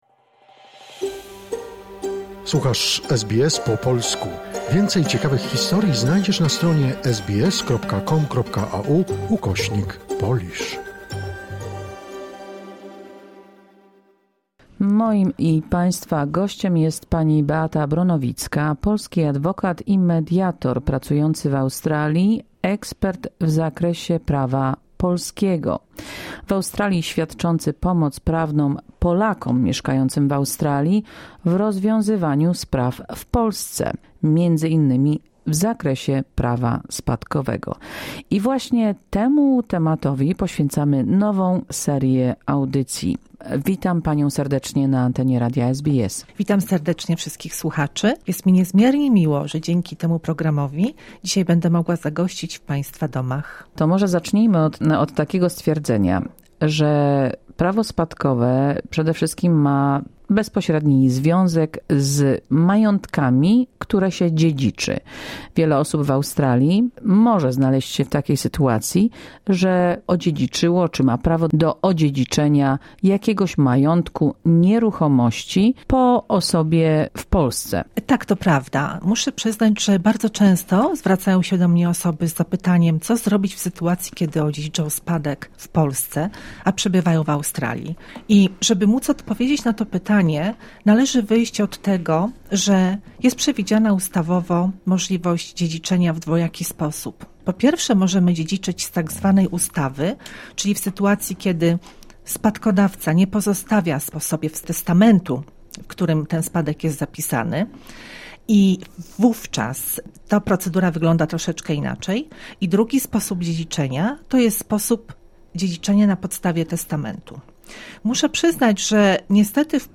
Nowy cykl rozmów o spadkach i dziedziczeniu majątku pozostawionego w Polsce.